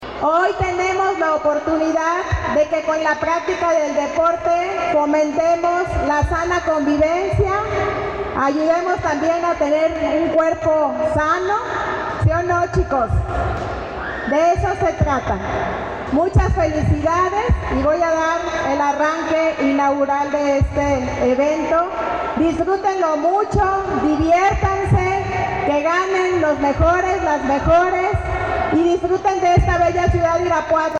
Lorena Alfaro, presidenta municipal